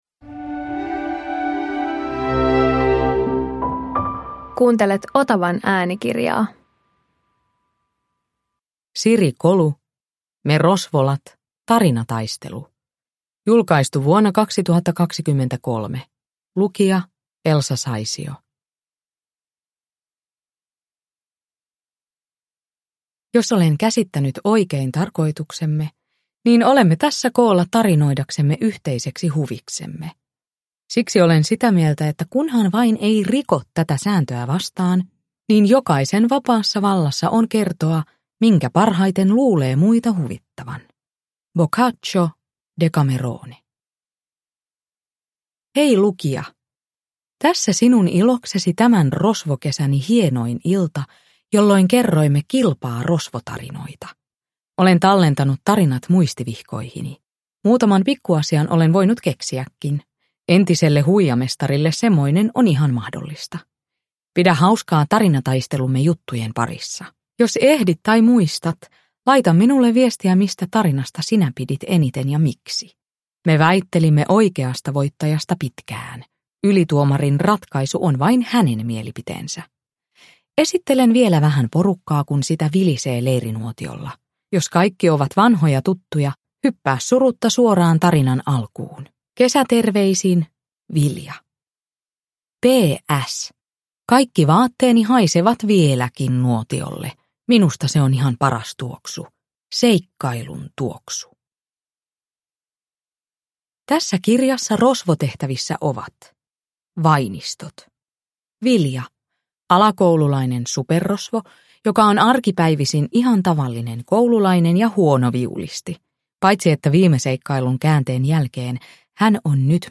Tarinataistelu – Ljudbok – Laddas ner
Uppläsare: Elsa Saisio